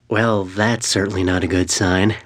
vs_fMortexx_sick.wav